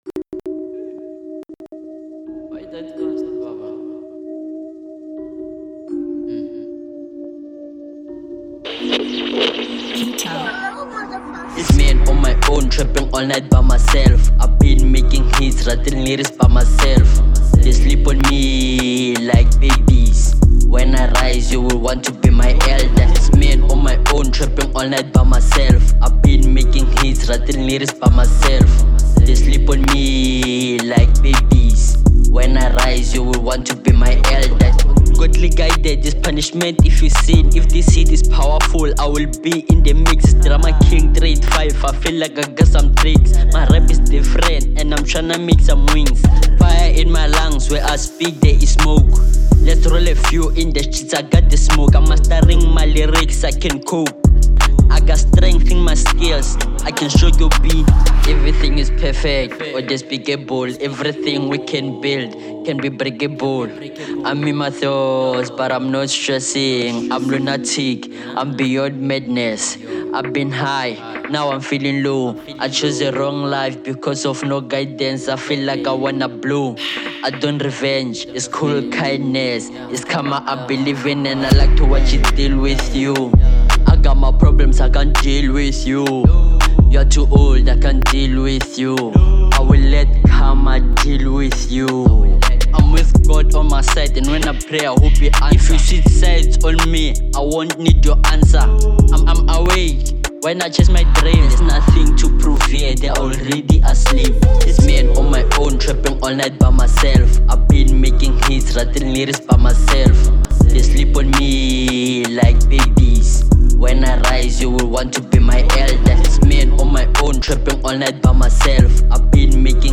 02:31 Genre : Hip Hop Size